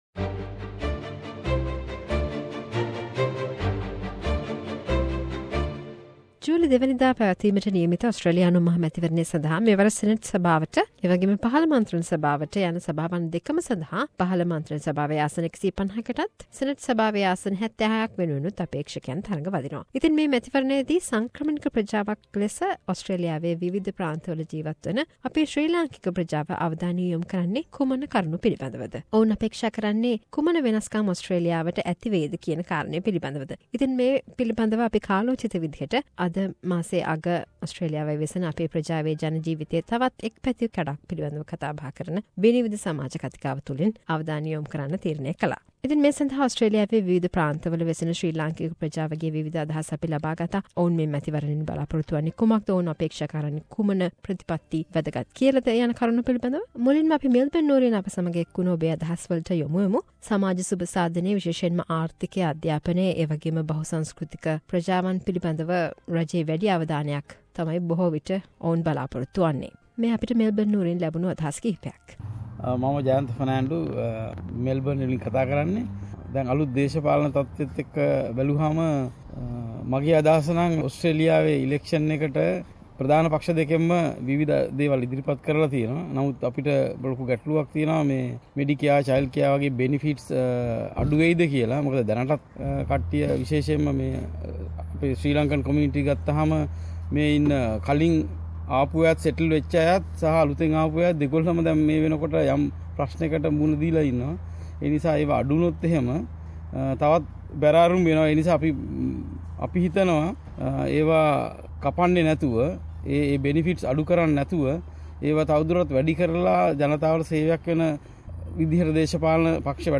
SBS Sinhalese monthly panel discussion focused on thoughts and hopes of the Sri Lankan community towards Australian Federal Election 2016